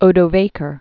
(ōdō-vākər)